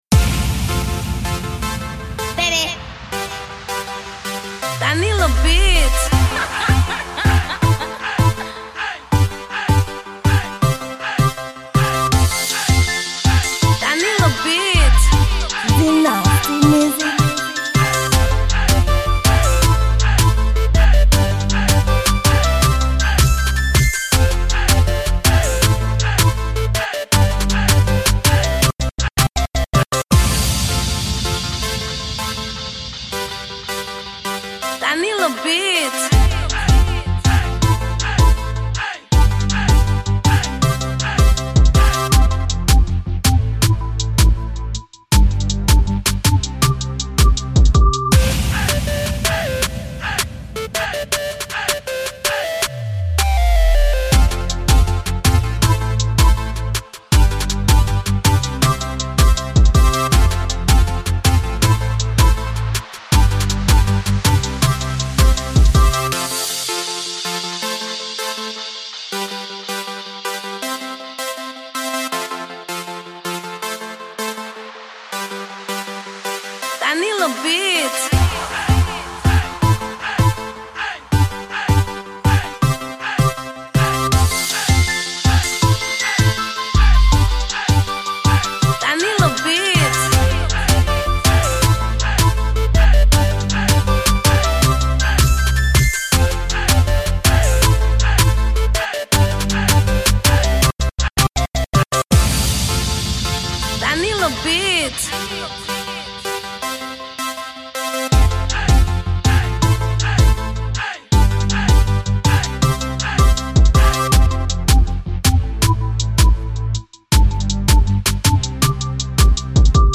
Genre: Beat